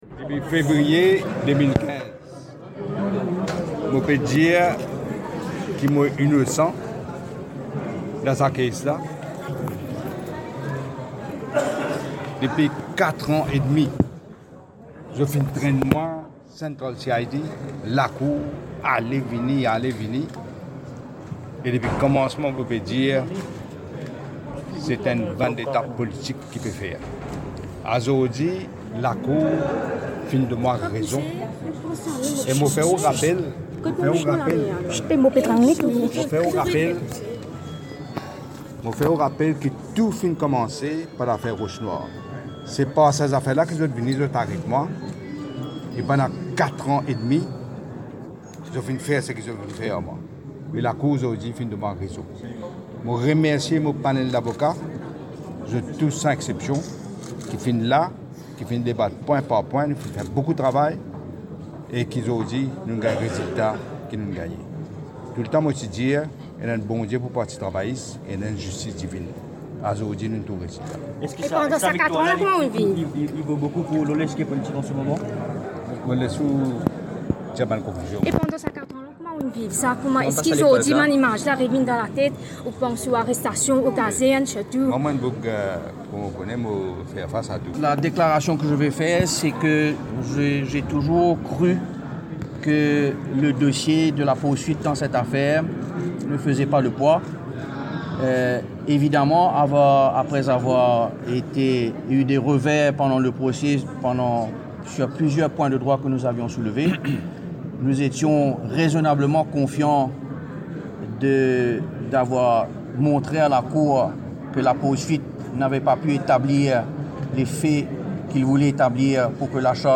Réaction de Navin Ramgoolam ce vendredi 13 septembre, en cour intermédiaire.